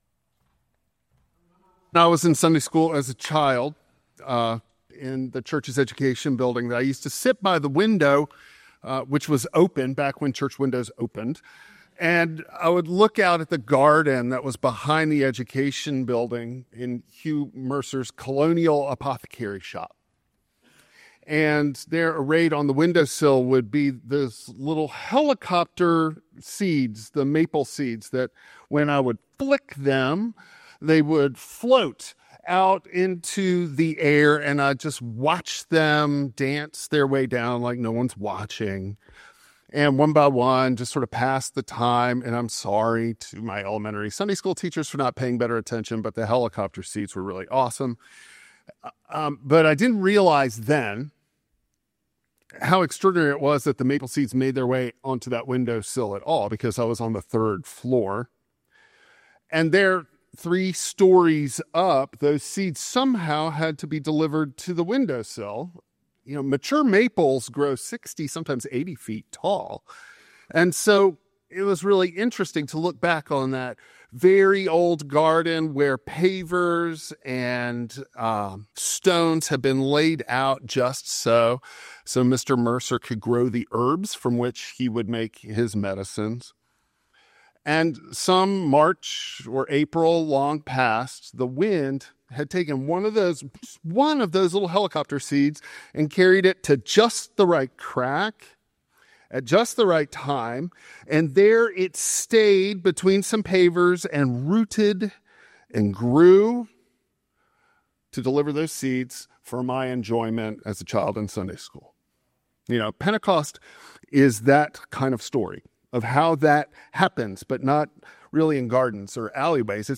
Service Type: Traditional Service